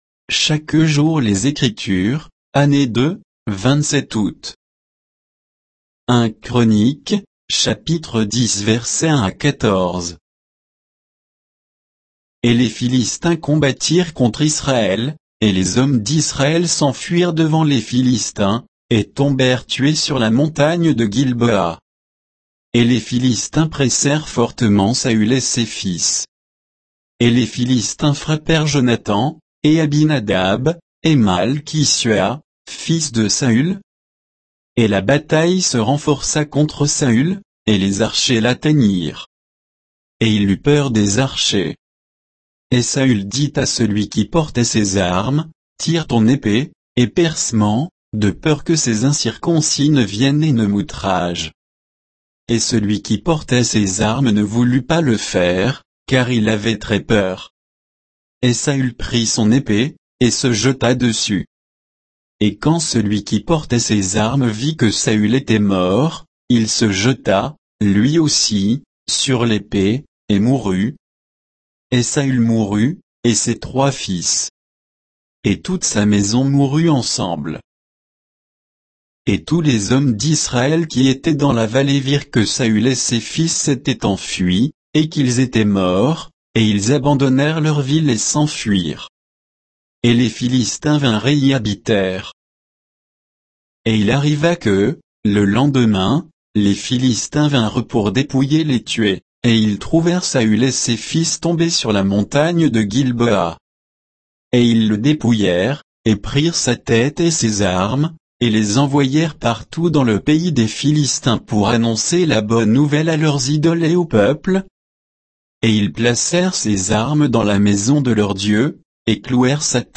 Méditation quoditienne de Chaque jour les Écritures sur 1 Chroniques 10, 1 à 14